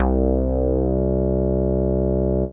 ARP BASS 3.wav